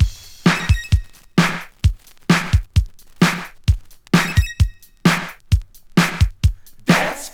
• 131 Bpm Fresh Disco Breakbeat F Key.wav
Free breakbeat sample - kick tuned to the F note. Loudest frequency: 1197Hz
131-bpm-fresh-disco-breakbeat-f-key-kCa.wav